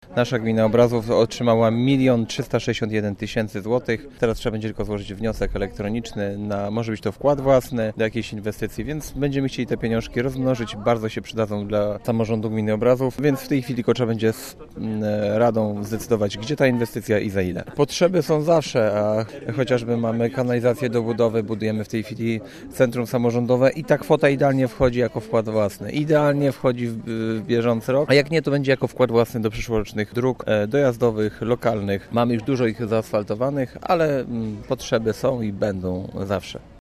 Mówi wójt gminy Obrazów Krzysztof Tworek: